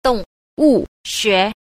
8. 動物學 – dòngwù xué – động vật học